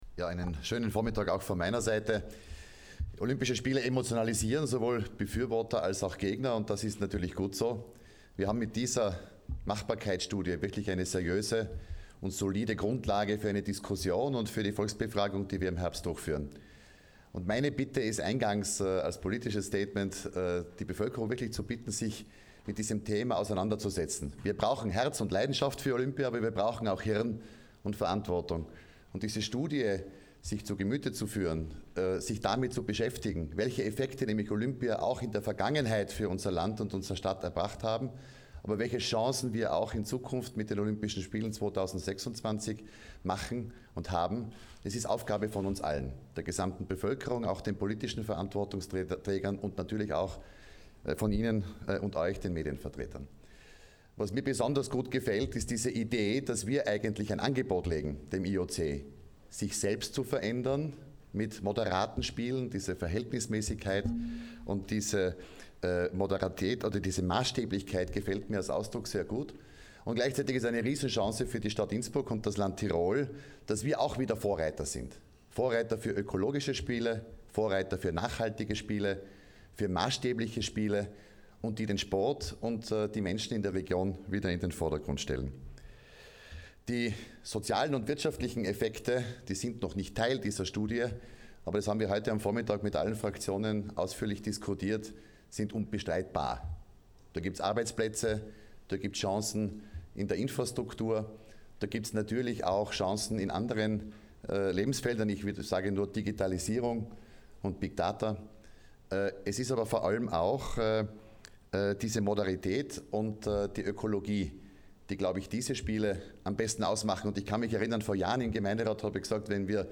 Stimmen zur Machbarkeitsstudie
Bürgermeisterin Christine Oppitz-Plörer